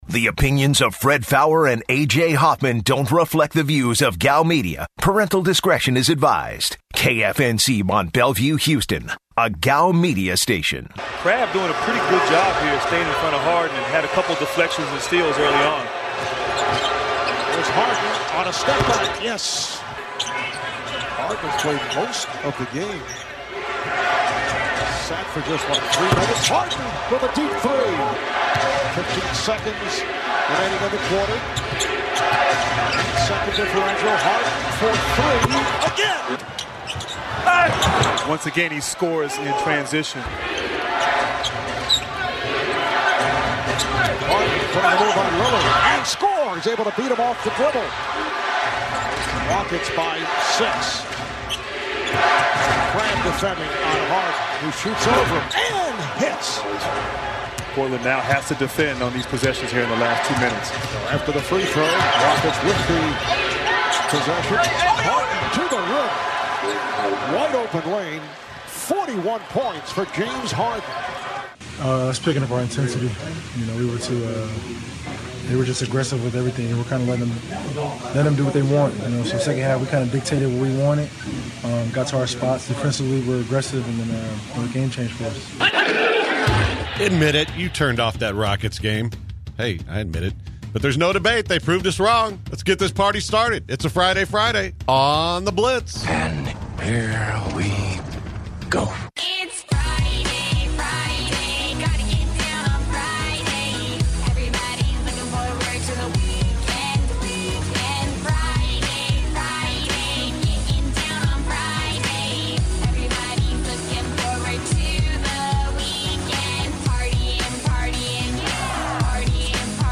The guys take some calls about the top running backs in the upcoming NFL Draft.